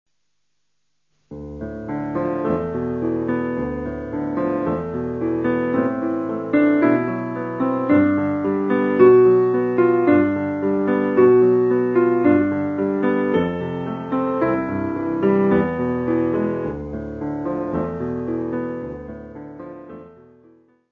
Music Category/Genre:  Classical Music